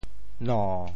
槈 部首拼音 部首 木 总笔划 14 部外笔划 10 普通话 nòu 潮州发音 潮州 no7 文 nao7 文 中文解释 耨 <动> (会意。